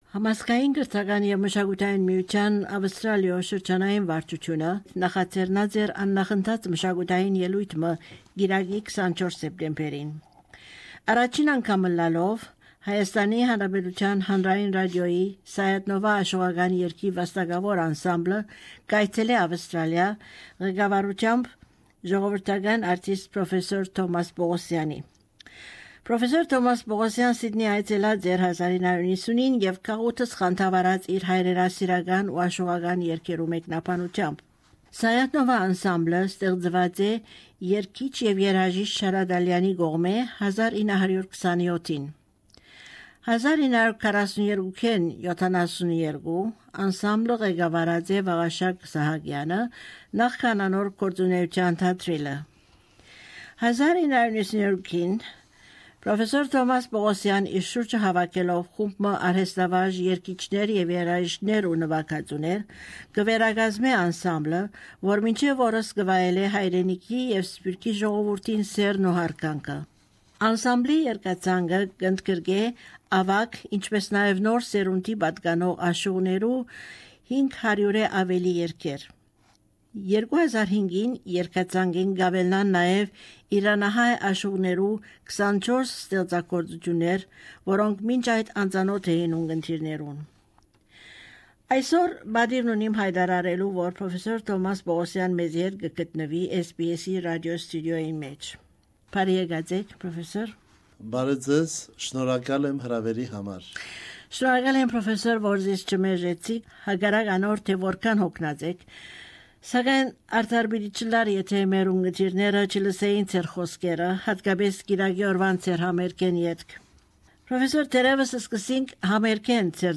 Interview
at the SBS Rasio studios